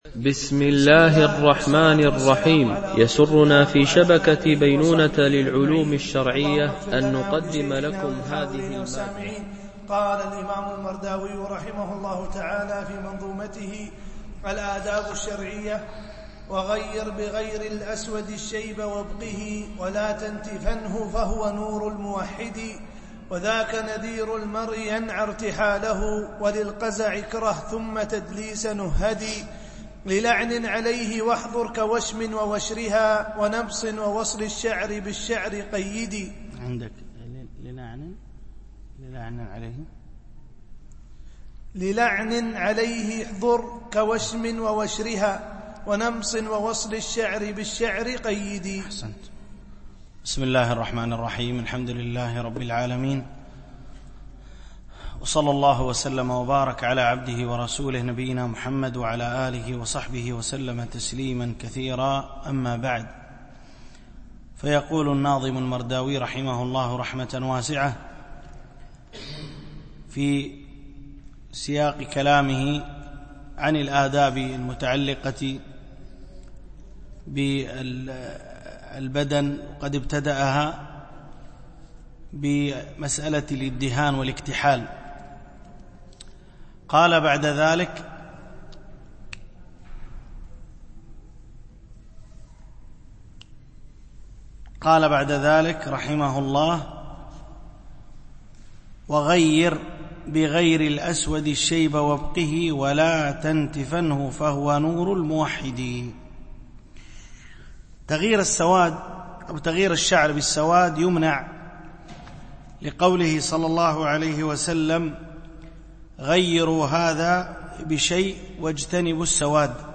شرح منظومة الآداب الشرعية – الدرس13 ( الأبيات 155-166 )